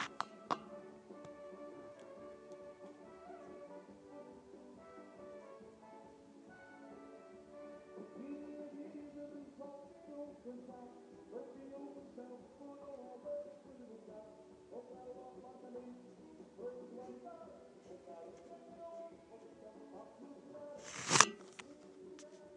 The first night of the festas that I've not drunk enough to be able to sleep through this.